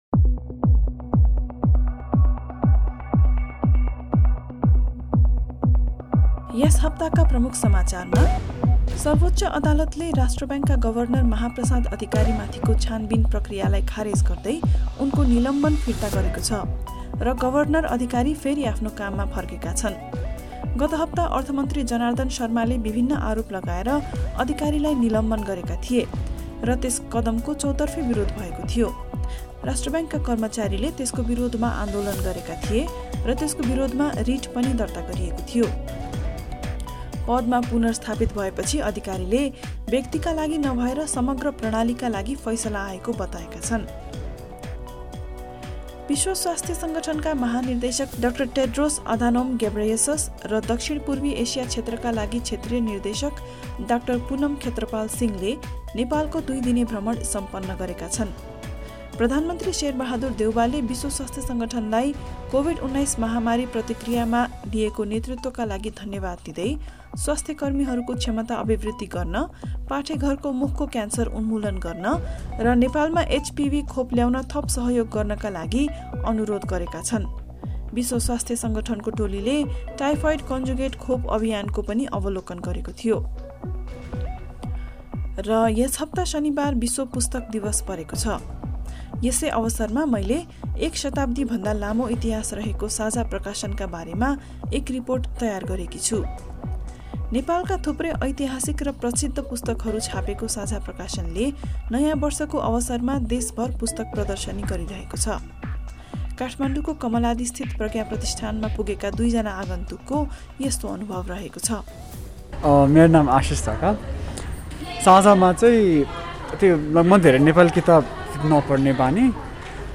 Listen to the latest news headlines from Australia in Nepali. In This Bulletin; The major parties campaign on Orthodox Easter Sunday with duelling spending promises, Ukraine vows to recapture lost territory when it has enough weapons from its Western allies, And in sport, Iga Swiatek to meet new world number one Aryna Sabalenka in the final of the Stuttgart tennis Open.